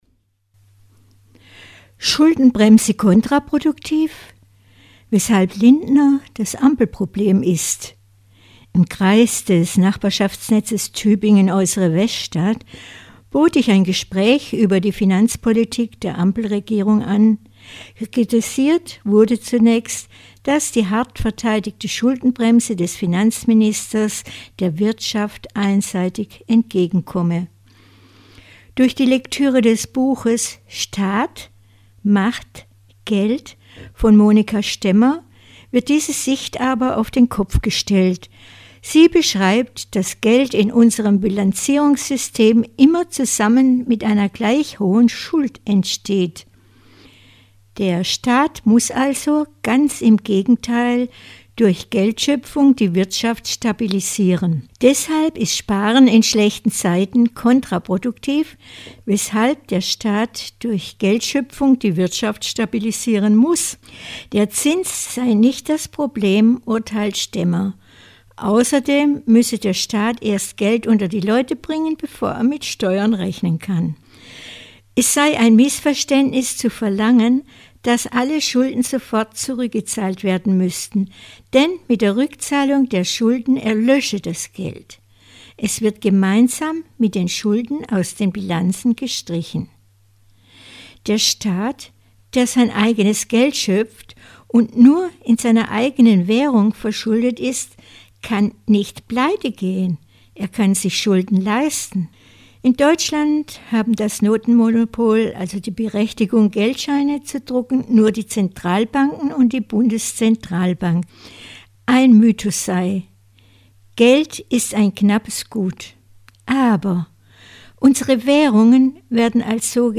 Vortrag: Schuldenbremse kontraproduktiv